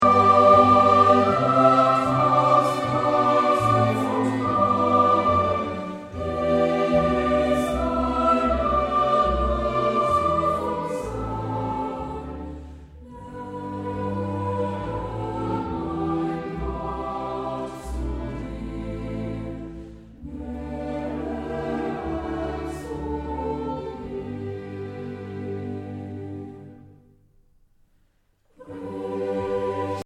Instrumentalsätze für variable Besetzungen